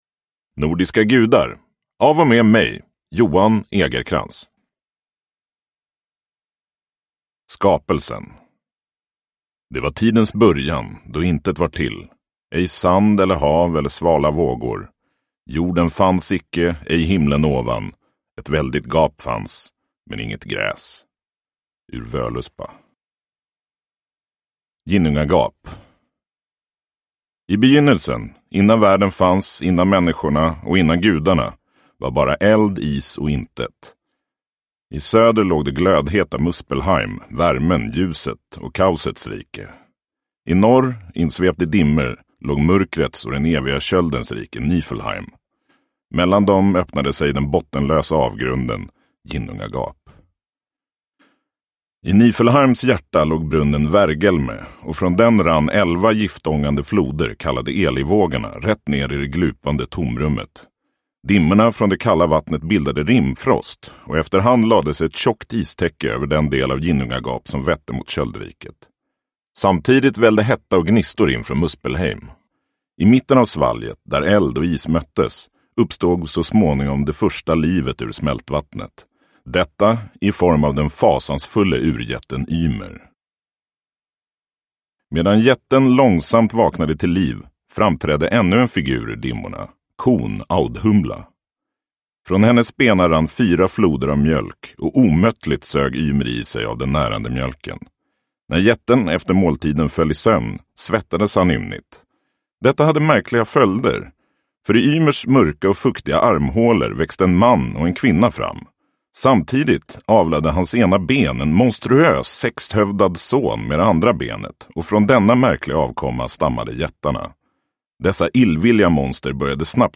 Nordiska gudar – Ljudbok – Laddas ner